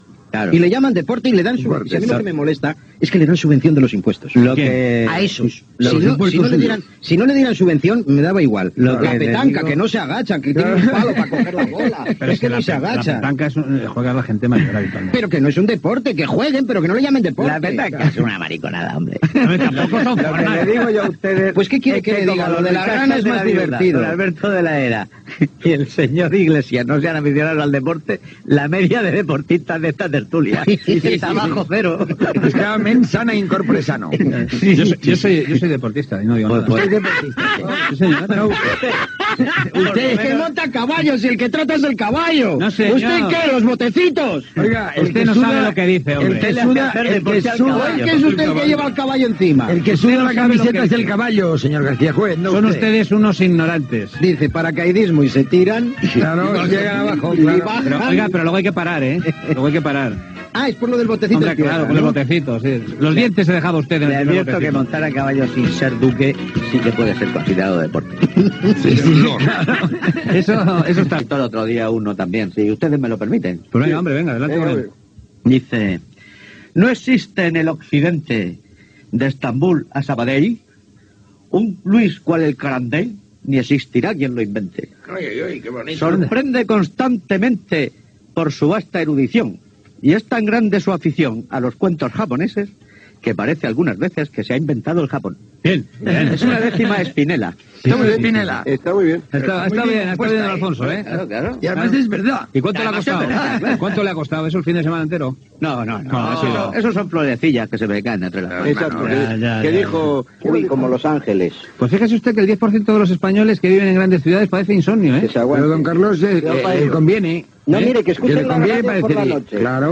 Fragment de la tertúlia sobre els esports
Gènere radiofònic Entreteniment